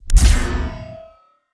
SCIEnrg_Shield Activate_05_SFRMS_SCIWPNS.wav